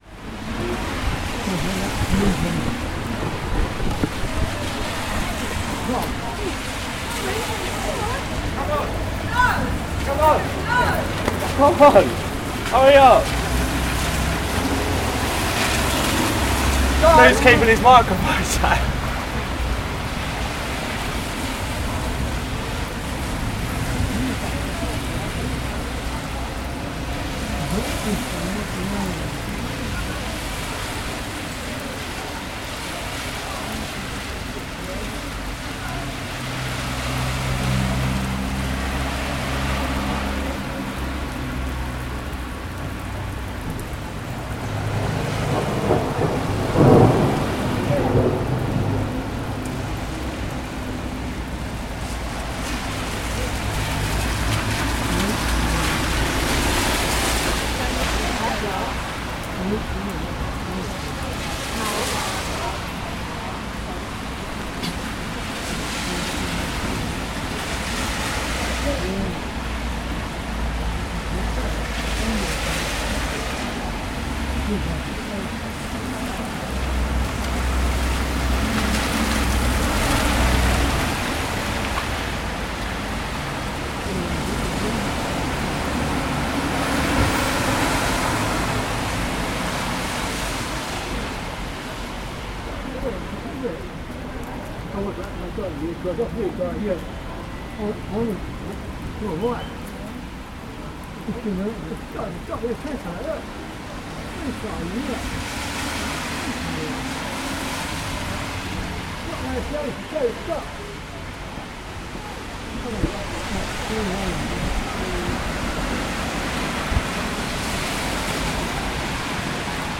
Margate thunderstorm
Thunderstorm as heard from Cafe G, Margate, June 2015.
Part of the Cities and Memory Margate sound map for Dreamland.